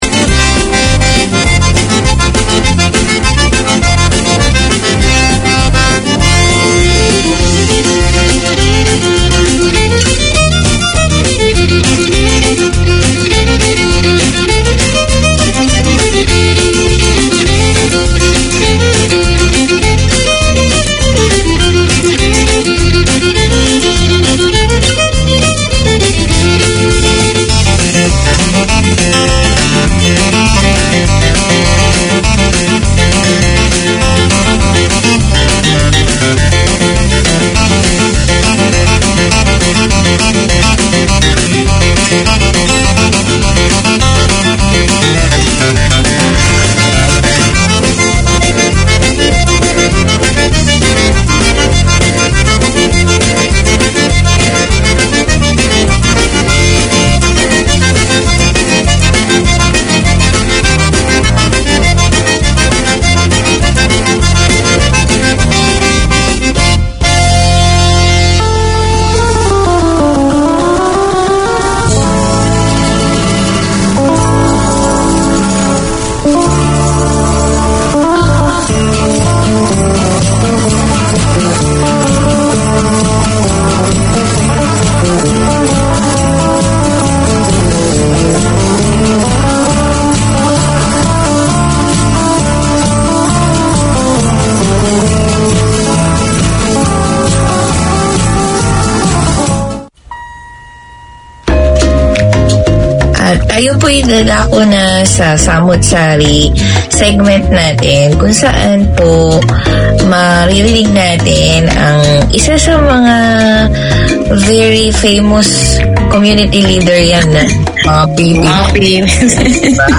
Write in, phone in to this Filipino family and community show. There are topics for migrants, Mga Balitang Pilipino and news of local and international Filipino successes.
And don't miss Trabaho Agad and the ten minute free phone-in community noticeboard.